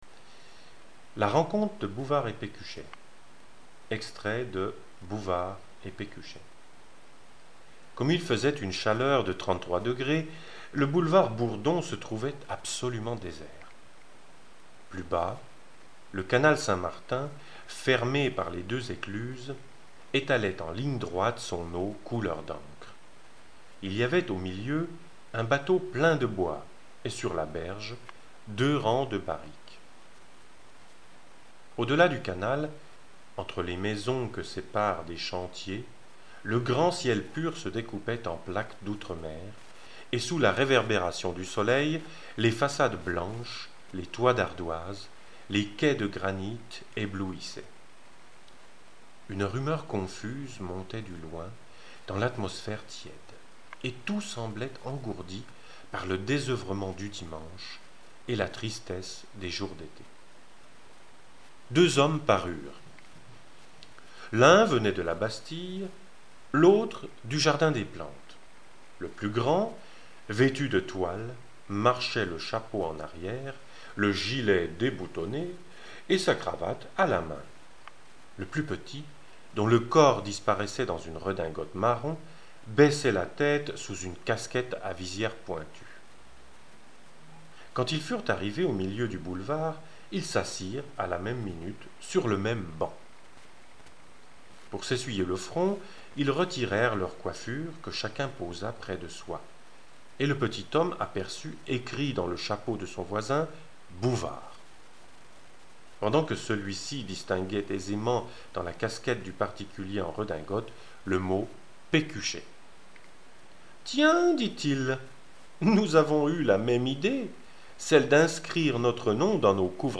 liest den Anfang von > Bouvard und Pécuchet: